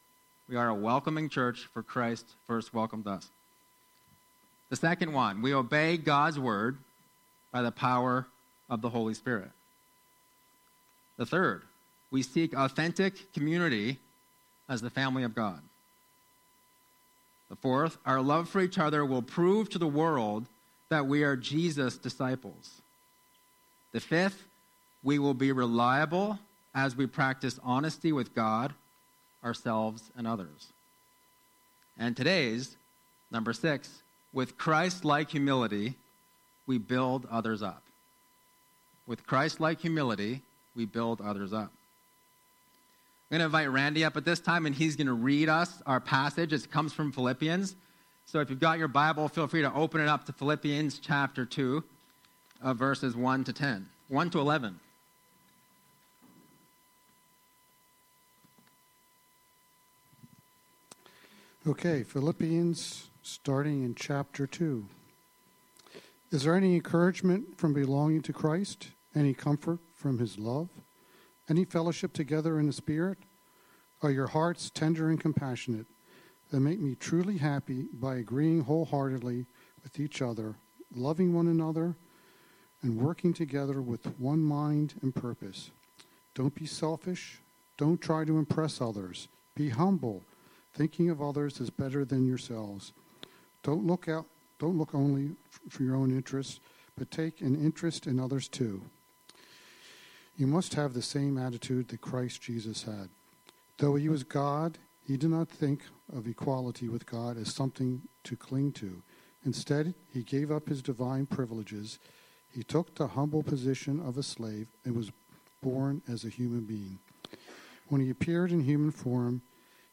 Sunday Messages | Transformation Church